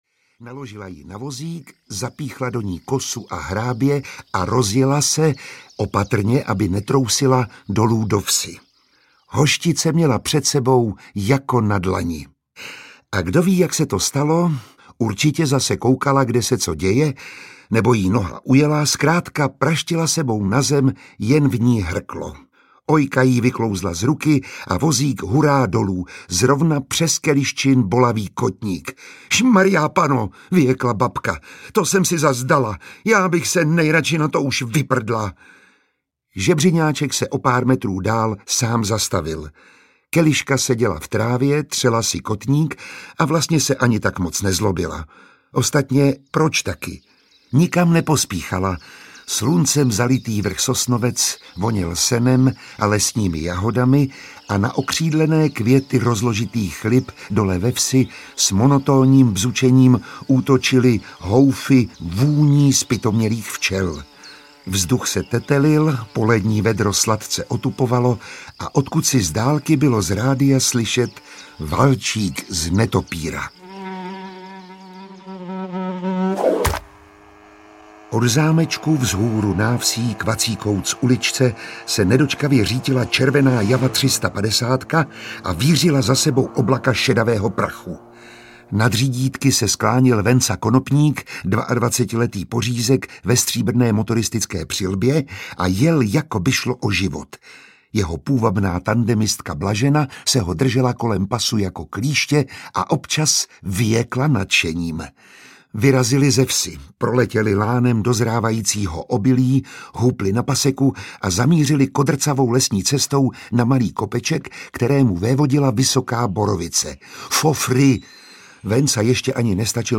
Literární zpracování legendárních filmových komedií v audioknižní podobě. Čte Miroslav Táborský, režie Zdeněk Troška.
Ukázka z knihy
Režisér Zdeněk Troška v nahrávacím studiu připravil „film pro uši“. Četbu herce Miroslava Táborského doplňuje řada zvukových efektů a známých hudebních motivů, takže posluchač si celý příběh skvěle vychutná.
• InterpretMiroslav Táborský